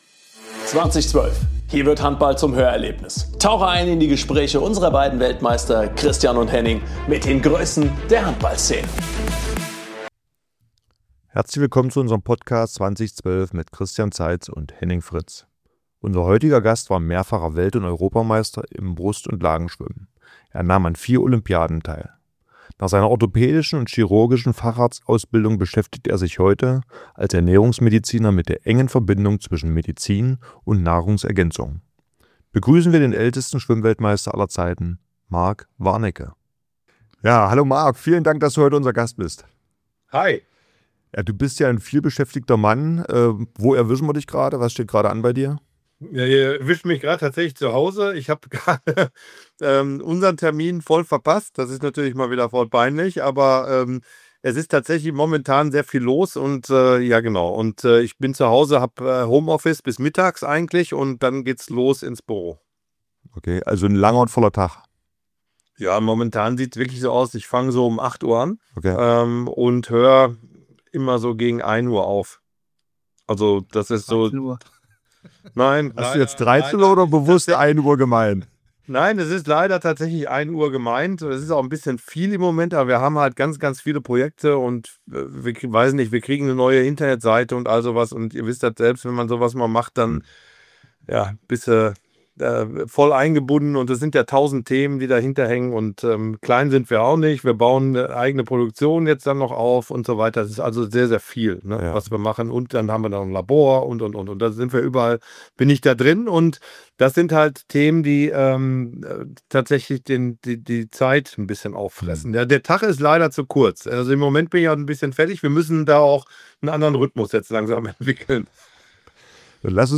Christian Zeitz und Henning Fritz sprechen mit Mark über Disziplin, mentale Stärke, den Weg vom Spitzensport in die Medizin und Unternehmerszene – und über unvergessliche Geschichten aus dem Olympischen Dorf 2000, als Schwimmer und Handballer gemeinsam den olympischen Spirit erlebten.